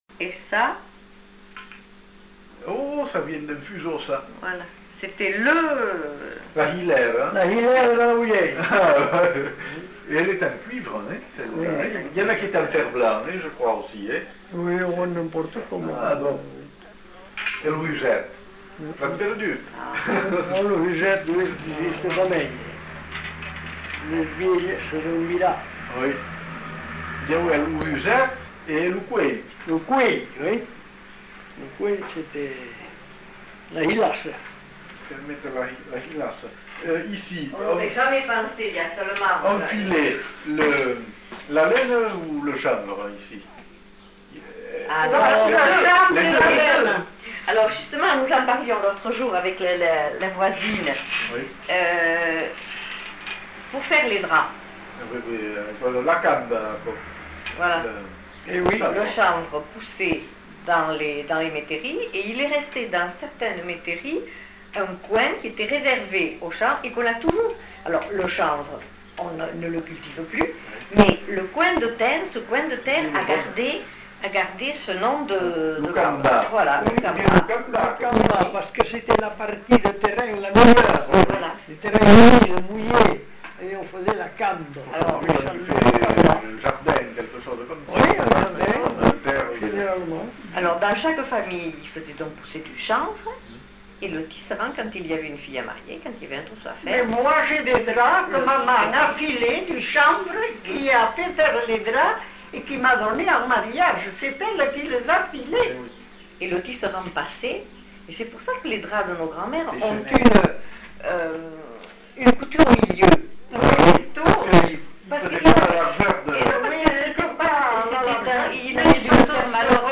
Lieu : Captieux
Genre : témoignage thématique